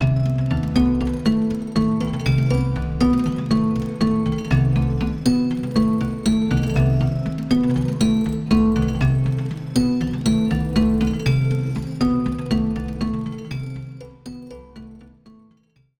No other processing was done on these sounds other than the onboard effects included with the instrument.
Organic Arps
Arpeggiated sounds with a convenient chord generator: